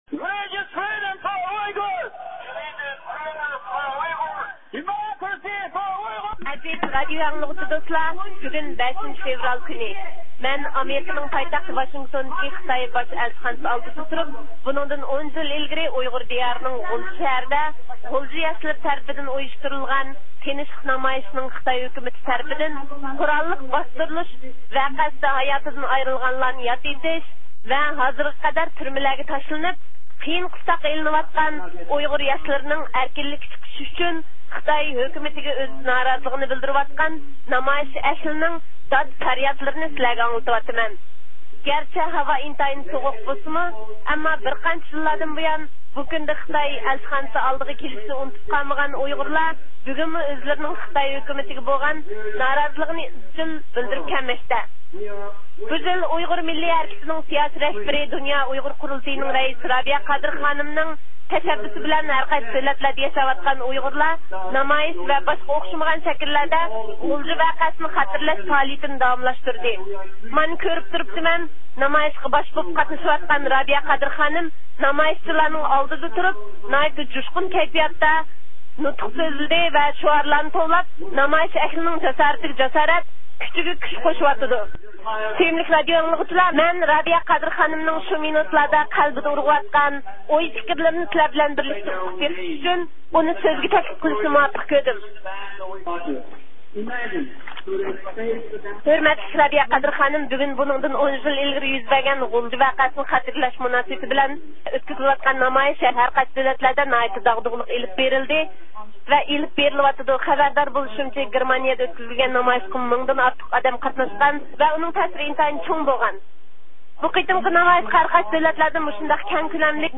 بۈگۈن 5 - فېۋرال كۈنى، مەن ئامېرىكىنىڭ پايتەختى ۋاشىنگتوندىكى خىتاي باش ئەلچىخانىسى ئالدىدا تۇرۇپ، بۇنىڭدىن 10 يىل ئىلگىرى ئۇيغۇر دىيارىنىڭ غۇلجا شەھىرىدە، غۇلجا ياشلىرى تەرىپىدىن ئۇيۇشتۇرۇلغان تىنچلىق نامايىشىنىڭ خىتاي ھۆكۈمىتى تەرىپىدىن قوراللىق باستۇرۇلۇش ۋەقەسىدە ھاياتىدىن ئايرىلغانلارنى ياد ئېتىش ۋە ھازىرغا قەدەر تۈرمىلەرگە تاشلىنىپ، قېيىن – قىستاققا ئېلىنىۋاتقان ئۇيغۇر ياشلىرىنىڭ ئەركىنلىككە چىقىشى ئۈچۈن خىتاي ھۆكۈمىتىگە ئۆز نارازىلىقىنى بىلدۈرۈۋاتقان نامايىش ئەھلىنىڭ دات – پەرياتلىرىنى سىلەرگە ئاڭلىتىۋاتىمەن.
مانا كۆرۈپ تۇرۇپتىمەن، نامايىشقا باش بولۇپ قاتنىشىۋاتقان رابىيە قادىر خانىم نامايىشچىلارنىڭ ئالدىدا تۇرۇپ، ناھايىتى جۇشقۇن كەيپىياتتا شۇئار توۋلاپ، نامايىش ئەھلىنىڭ جاسارىتىگە – جاسارەت، كۈچىگە - كۈچ قوشۇۋاتىدۇ.
نامايىش ئەھلى قوللىرىدا ئاي يۇلتۇزلۇق كۆك بايراق ۋە «ئۇيغۇرلارنىڭ كىشىلىك ھوقۇق دەپسەندىچىلىكى توختىلسۇن»، «ئۇيغۇرلارغا ئەركىنلىك»، » ئۇيغۇر سىياسىي مەھبۇسلىرى ئەركىنلىككە چىقسۇن»، «ئۇيغۇرلارغا دېموكراتىيە» دەپ يېزىلغان چوڭ خەتلىك لوزۇنكىلارنى ئىگىز كۆتۈرۈپ، جاراڭلىق شۇئار توۋلاشماقتا.